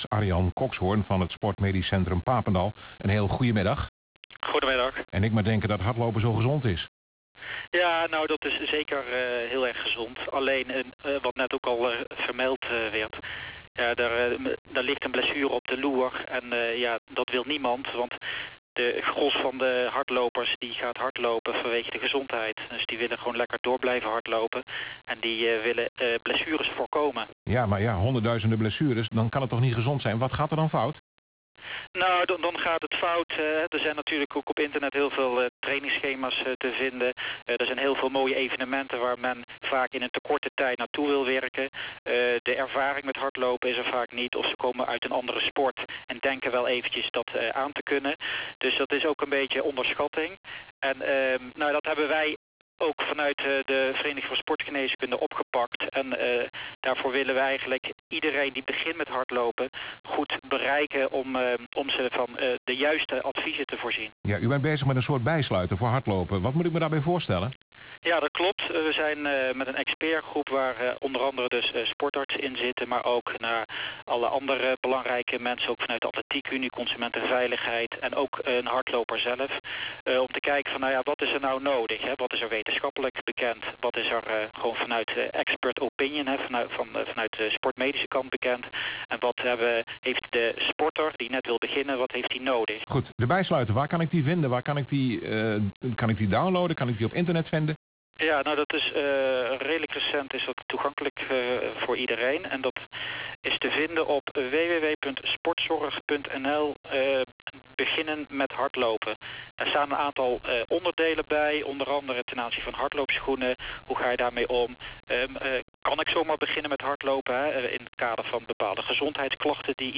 Radio-interview sportarts over beginnen met hardlopen